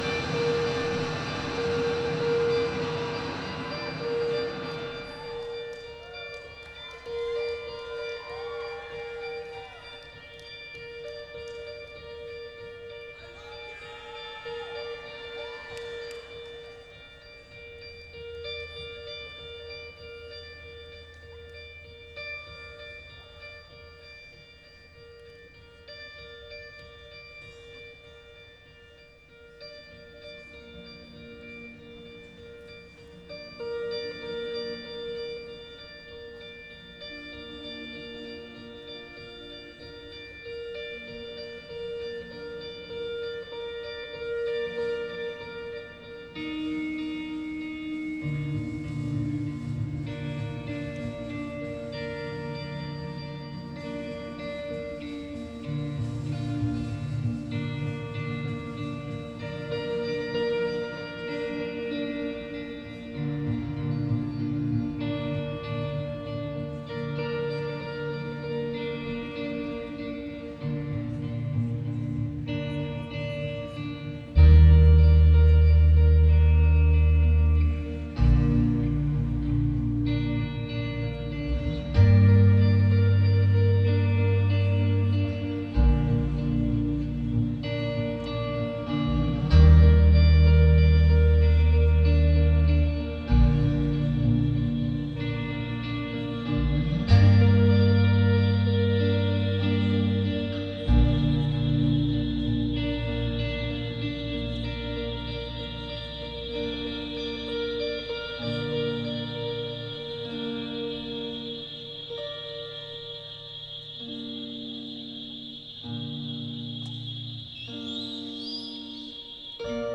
Post-rock